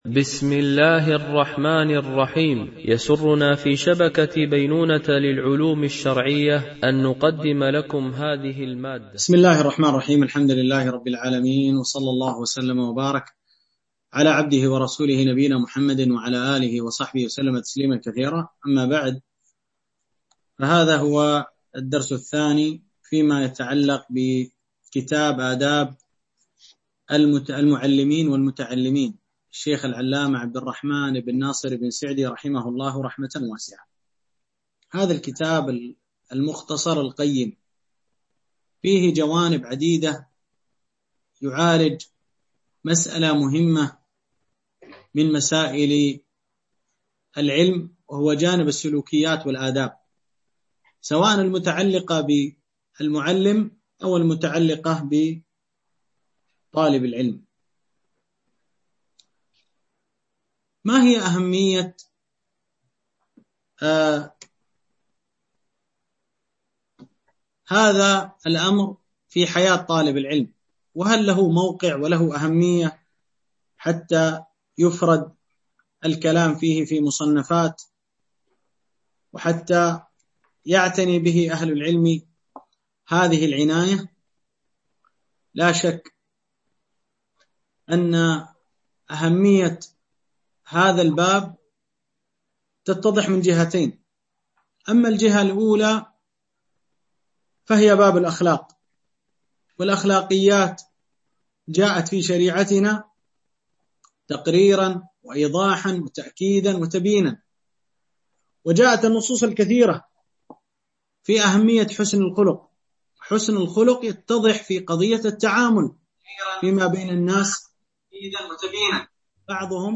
شرح آداب المعلمين والمتعلمين ـ الدرس 01
دورة علمية عن بعد